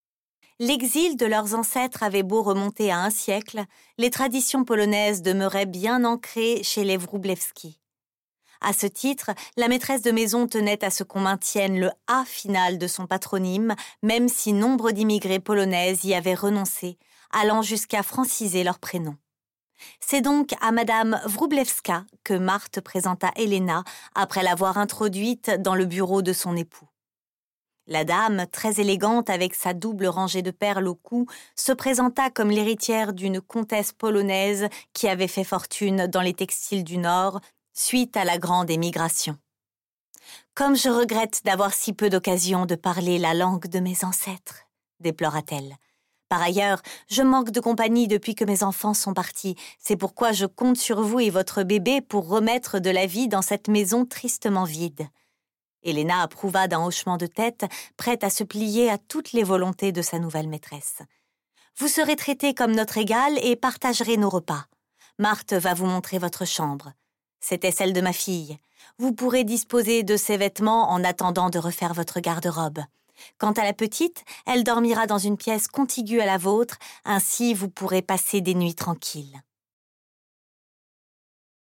My voice is young, dynamic, sensual, soft, luminous and smiling… Just like me !
AUDIOBOOK